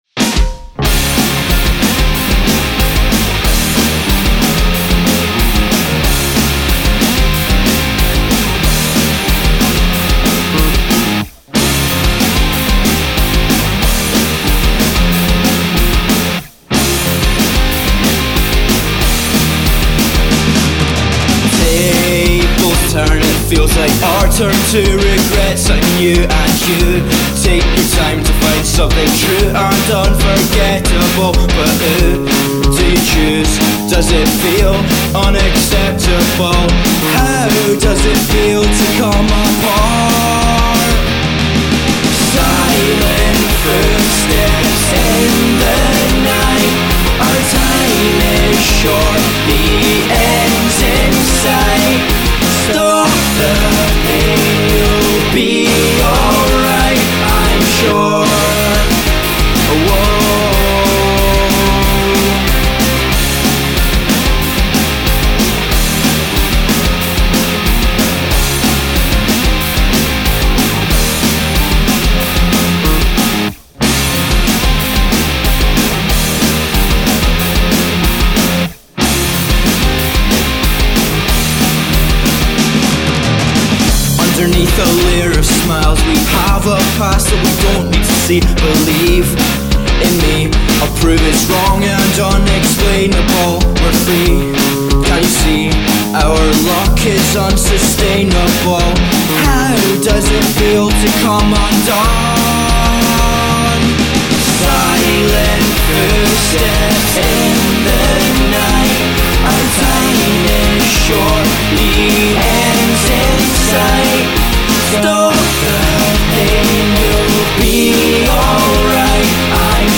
Case Study 2 - Remix